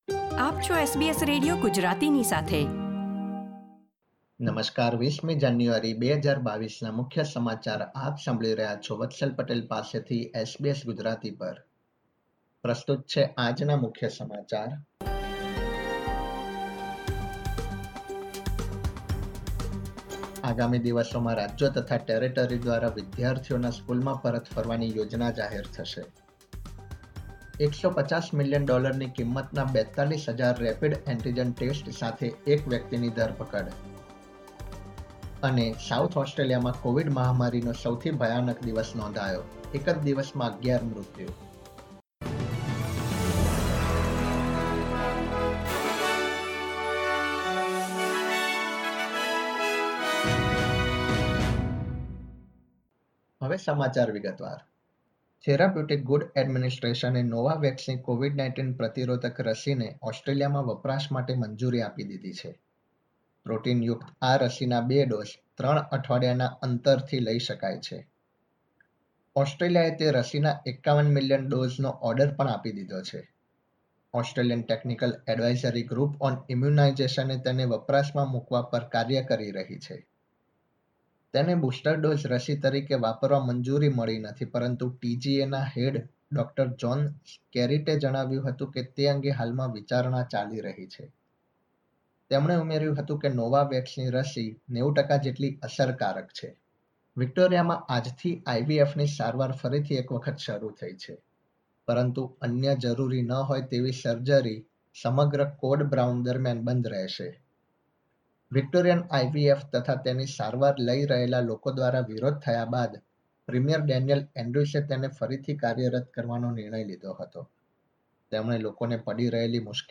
SBS Gujarati News Bulletin 20 January 2022